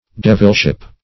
Search Result for " devilship" : The Collaborative International Dictionary of English v.0.48: Devilship \Dev"il*ship\, n. The character or person of a devil or the devil.